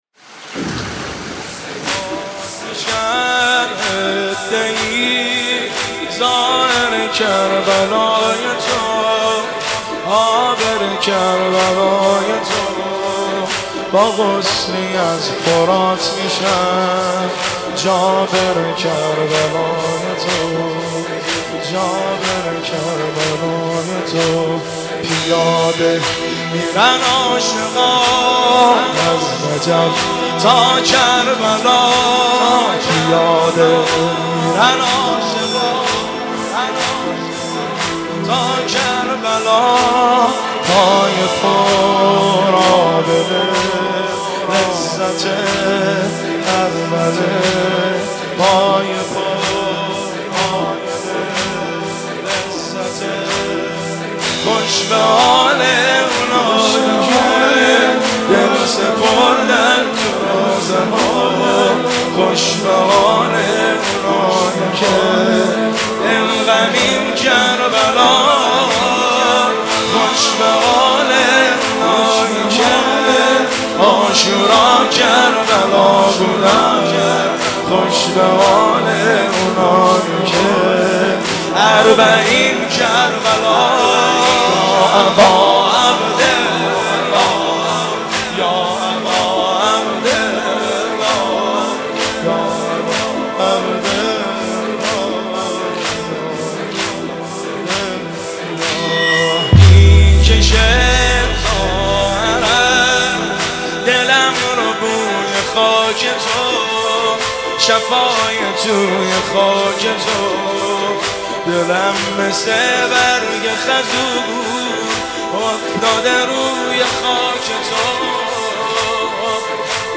نوحه
مداحی